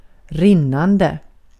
Uttal